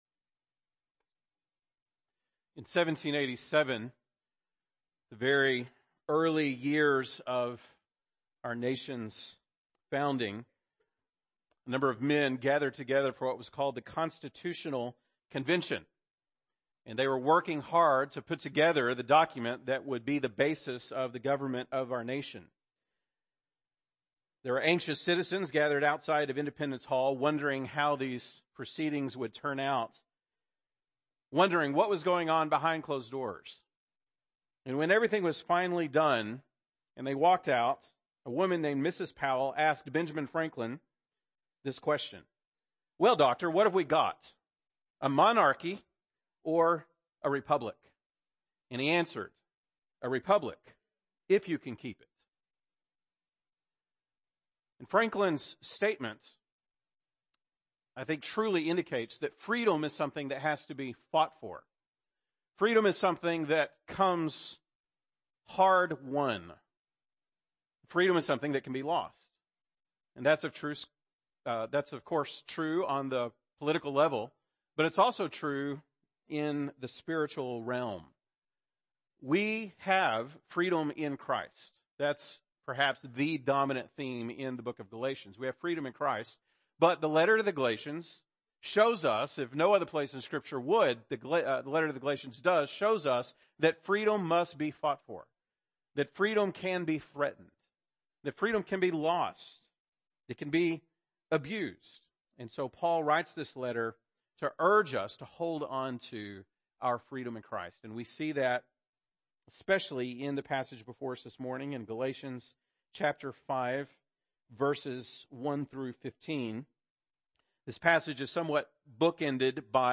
November 19, 2017 (Sunday Morning)